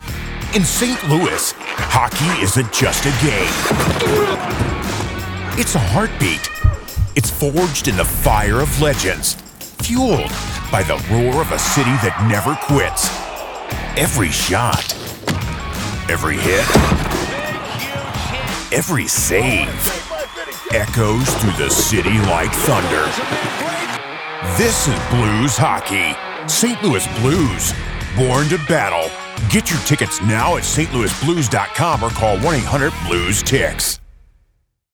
St. Louis Blues Radio Promo
Young Adult
Middle Aged